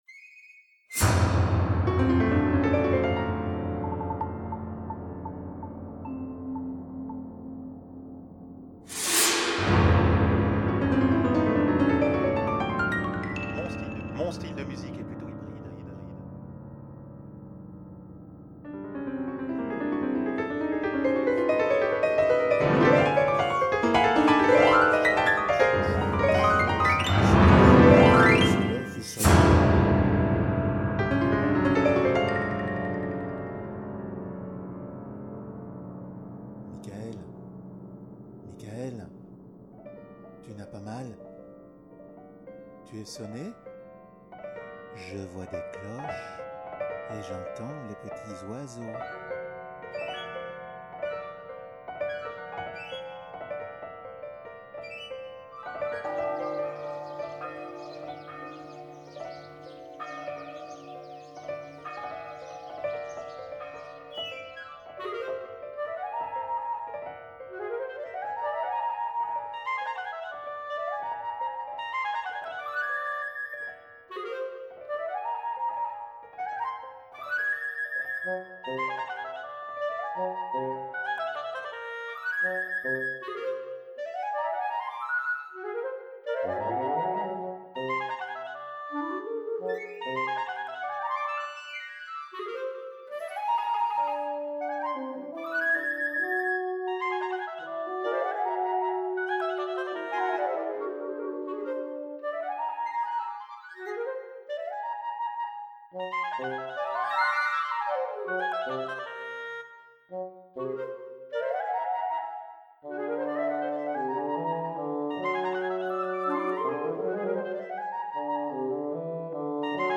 J'ai voulu faire une espèce de balayage un peu décalé de plusieurs périodes de la musique classique.
Je préviens d'avance qu'il y a pas mal de choses à revoir dans l'orchestration, mais l'idée est claire. Faites aussi attention à vos oreilles : la dynamique est assez large et le morceau commence fort.